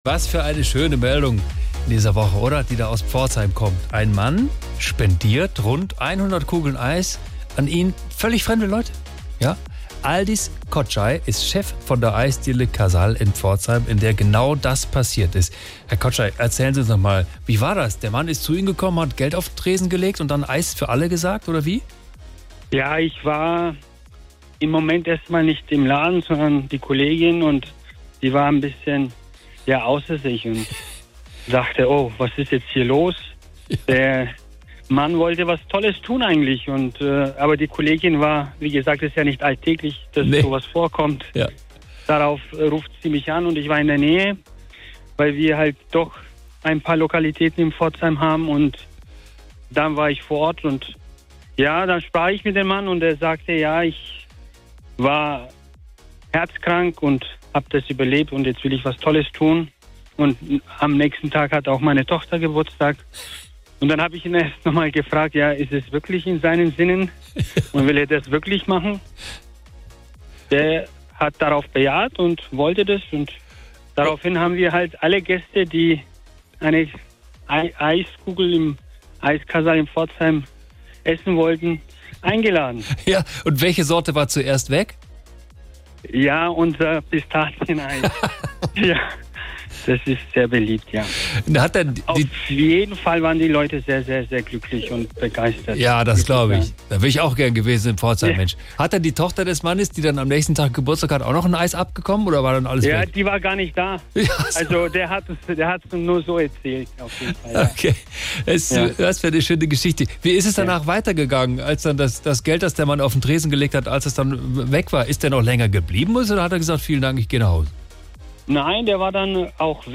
Hier das SWR3 Interview